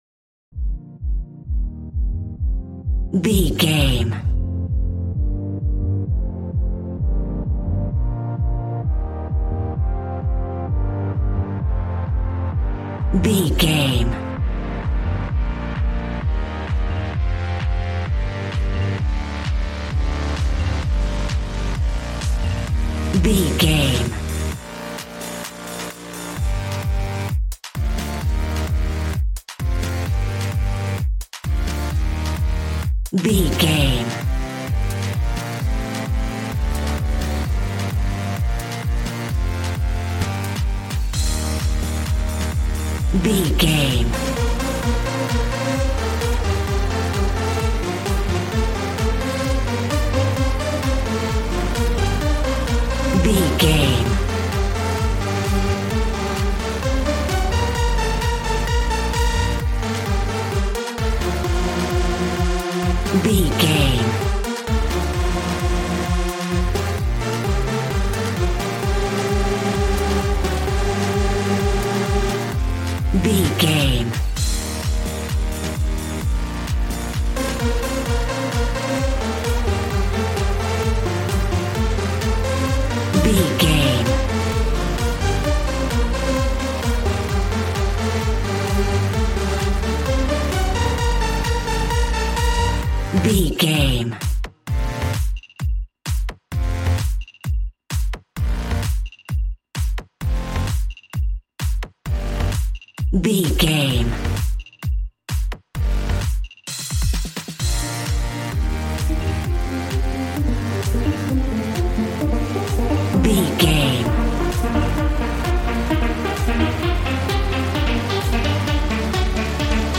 Aeolian/Minor
Fast
groovy
energetic
synthesiser
drums